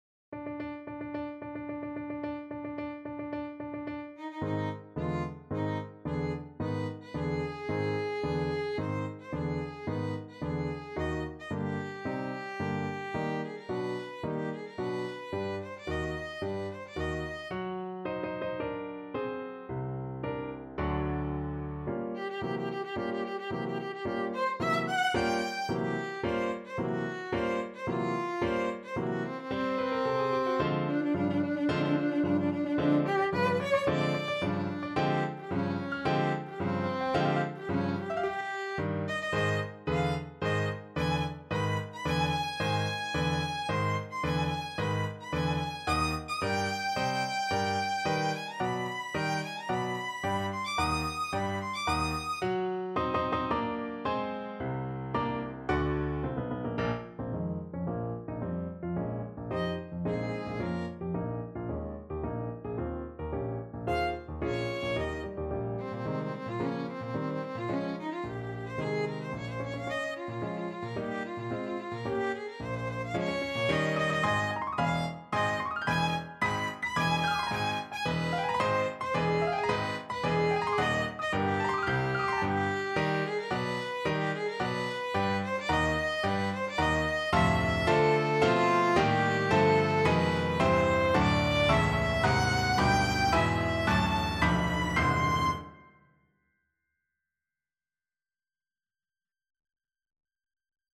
4/4 (View more 4/4 Music)
~ = 110 Tempo di Marcia
Classical (View more Classical Violin Music)